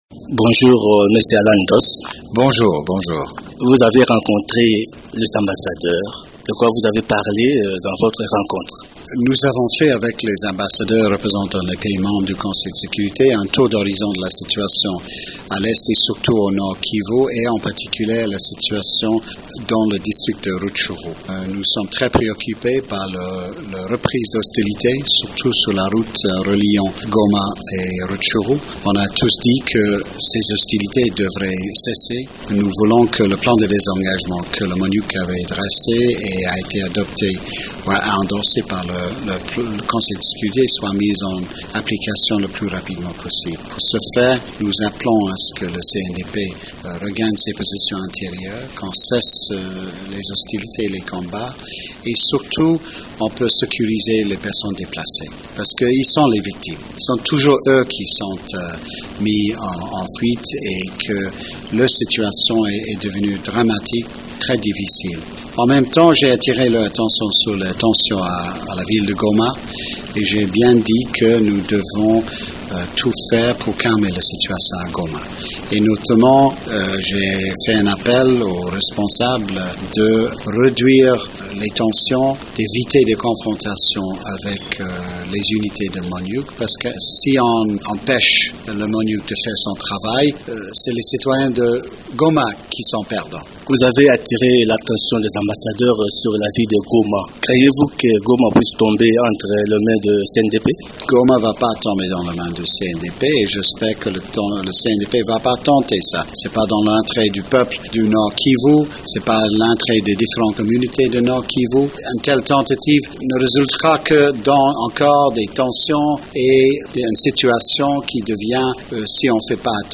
Allan Doss, représentant spécial du secrétaire général des nations unies en RDC répondait lundi soir au micro